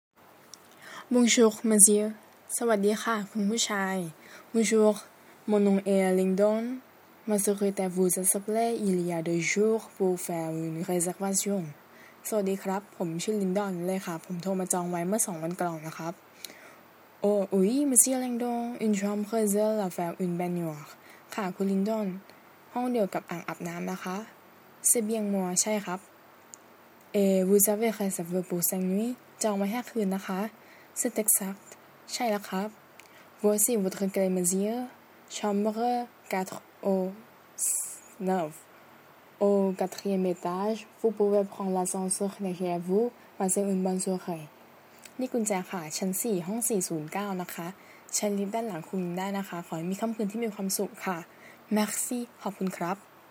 บทสนทนา14